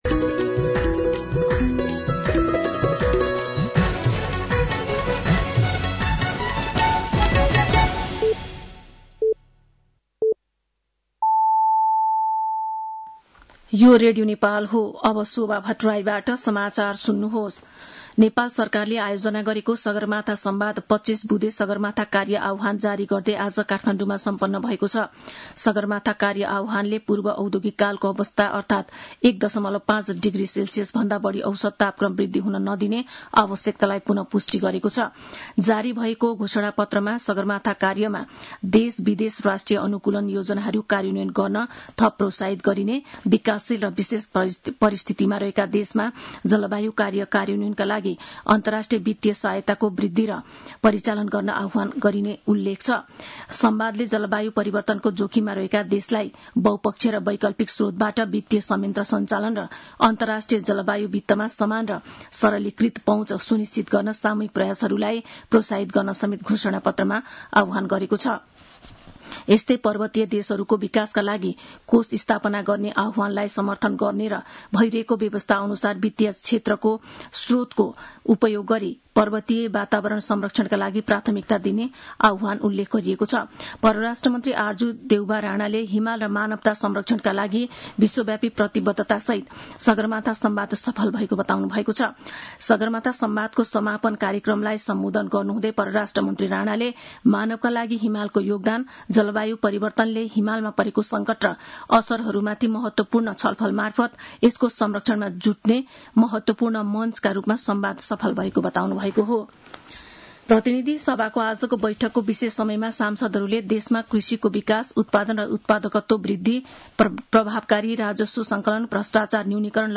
साँझ ५ बजेको नेपाली समाचार : ४ जेठ , २०८२
5-pm-news-3.mp3